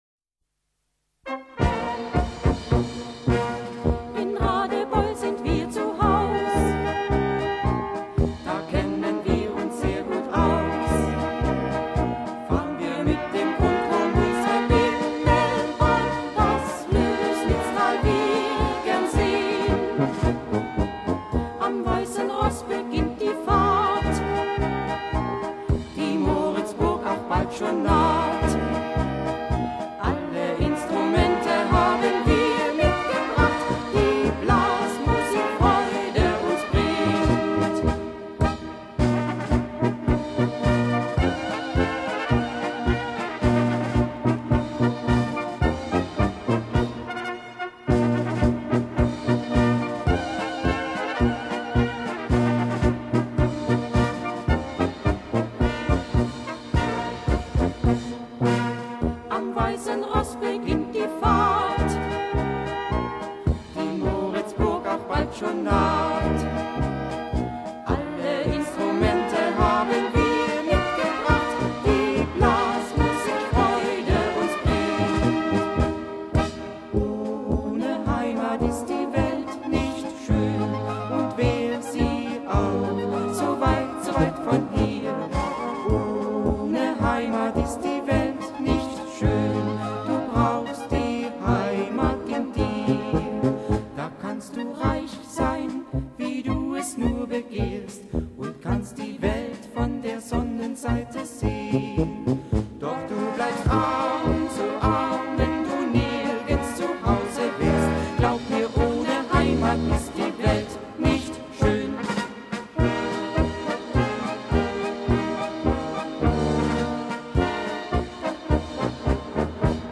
Polka mit Gesang